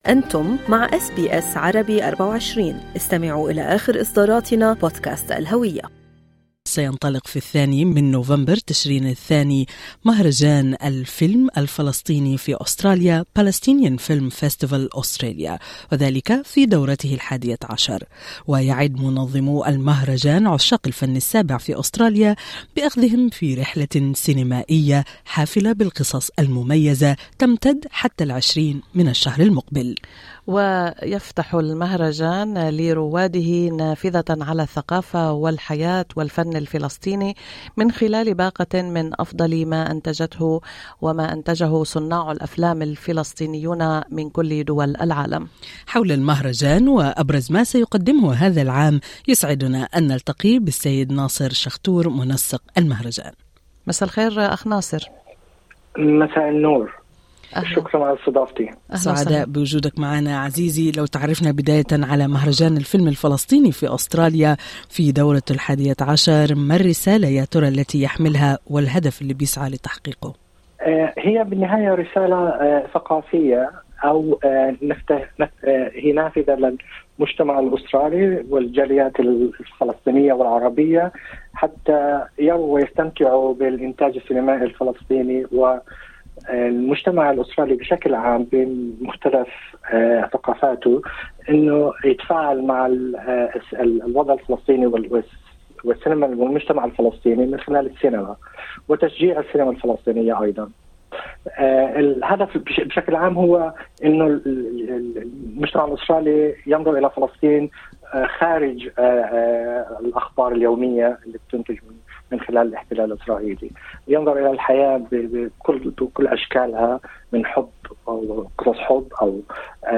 استمعوا إلى اللقاء كاملا في المدونة الصوتية في أعلى الصفحة.